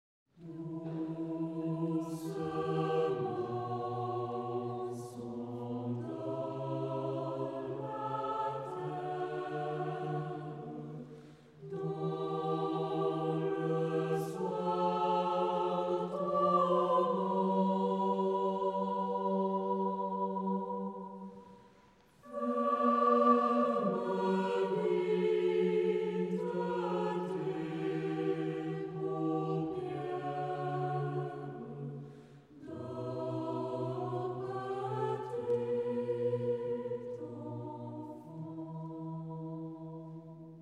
SAH A Cappella